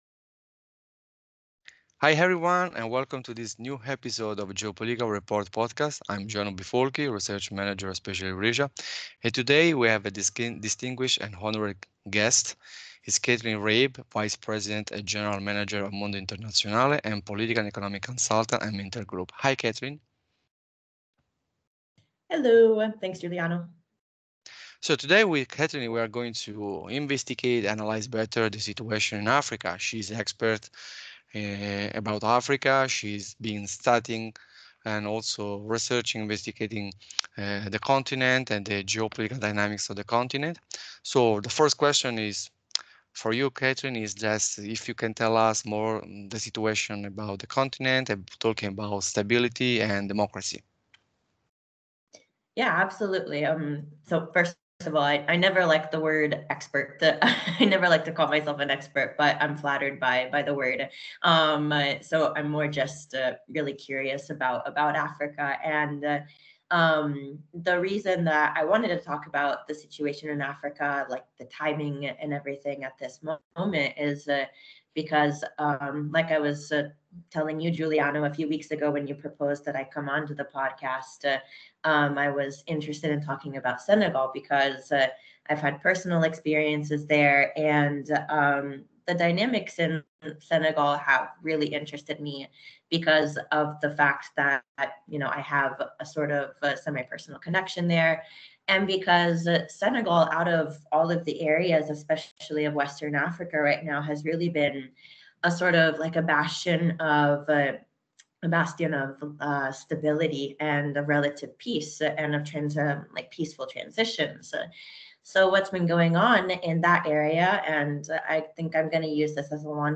Ep.6 – Geopolitics and Democracy in Africa: A Conversation